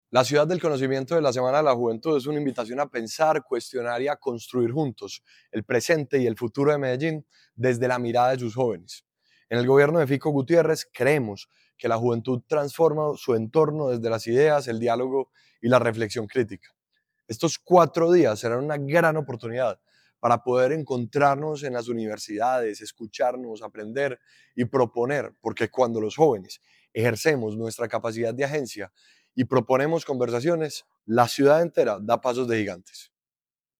Declaraciones del secretario de Juventud, Ricardo Jaramillo Vélez.
Declaraciones-del-secretario-de-Juventud-Ricardo-Jaramillo-Velez..mp3